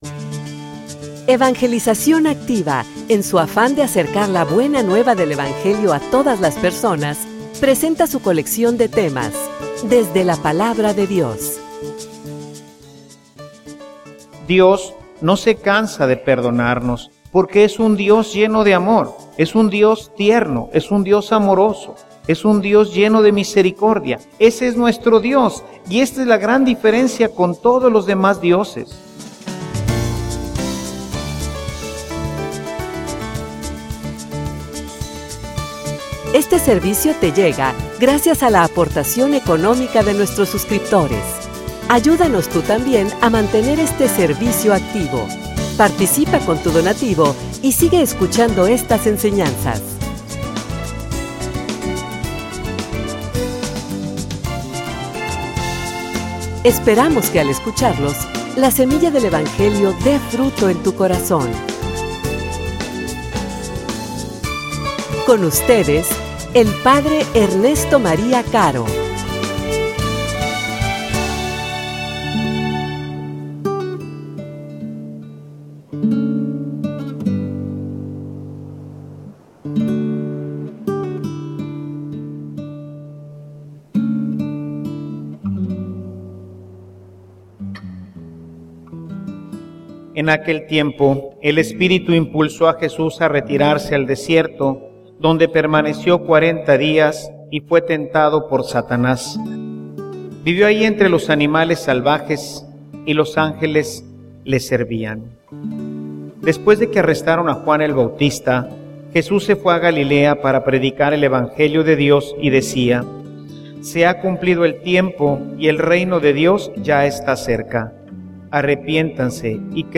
homilia_He_reservado_misericordia_para_ti.mp3